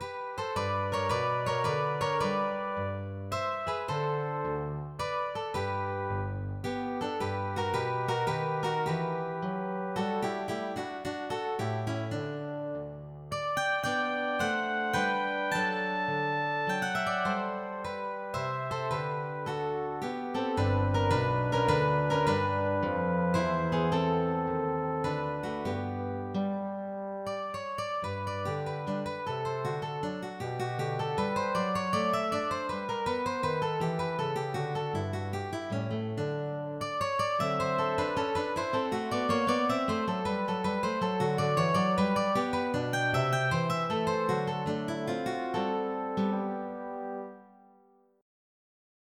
MIDI Music File
Type General MIDI
gminuet.mp3